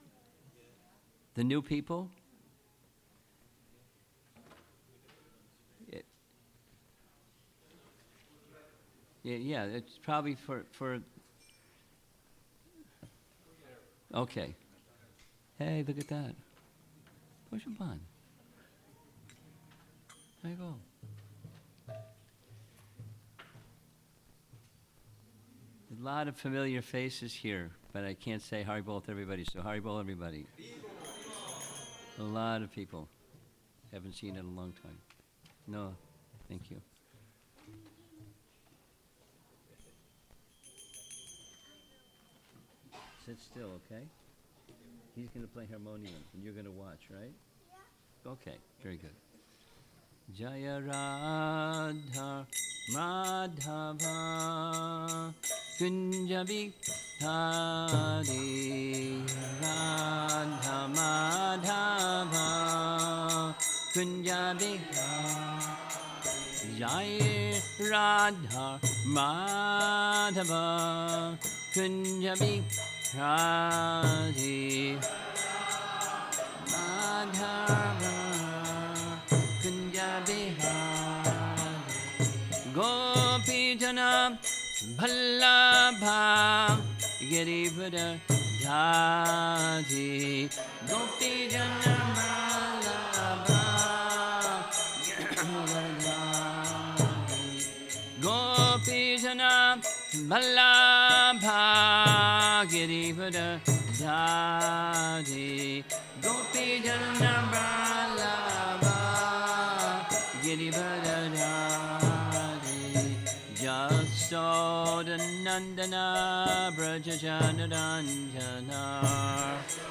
Sunday Feast Lecture